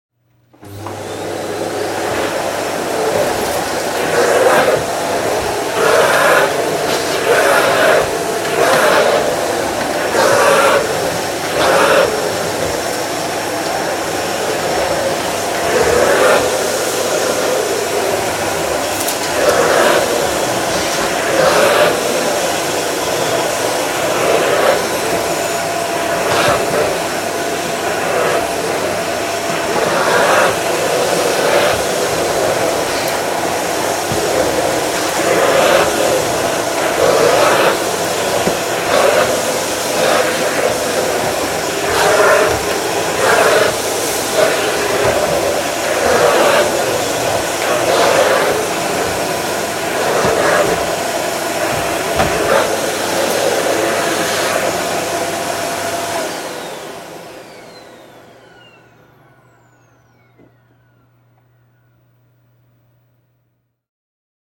Шум работающего пылесоса